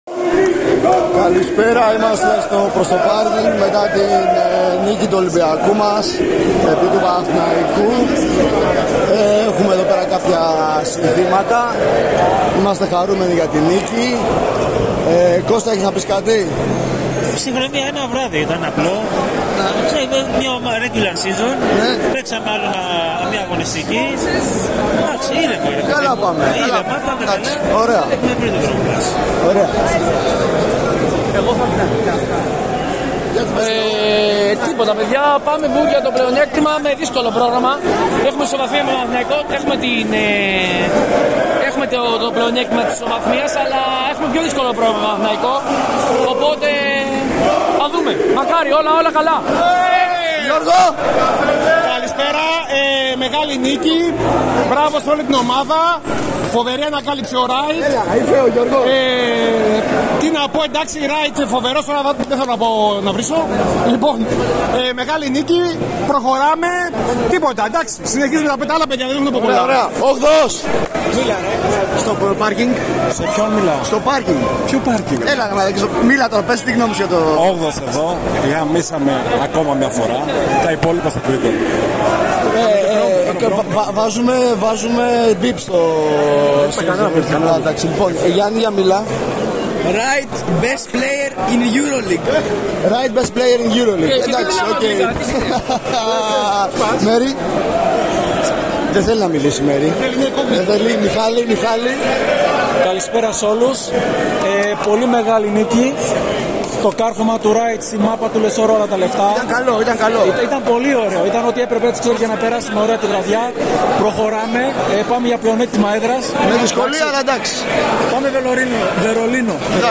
Σημ: Συγνώμη για κάποιες "κακές" λέξεις που ξέφυγαν πάνω στον ενθουσιασμό των Προς το Παρκινγκστων.